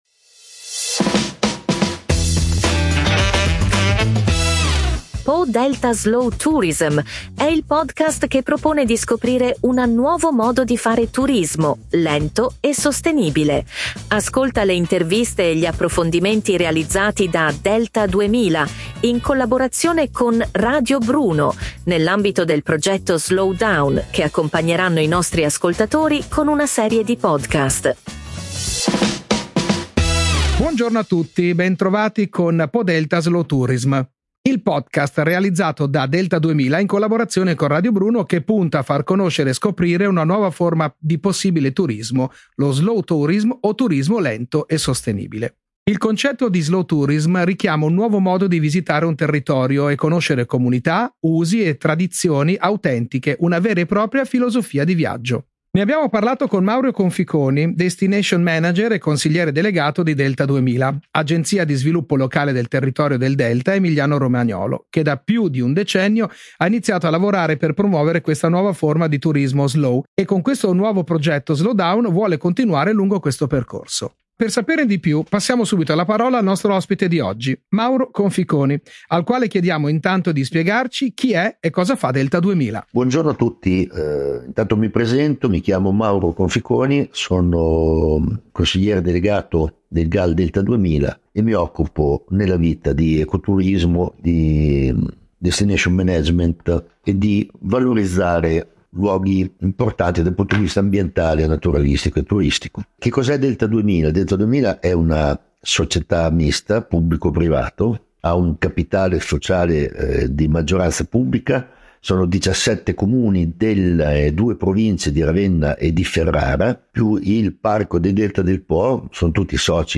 Ma per saperne di più passiamo subito la parola al nostro ospite di oggi.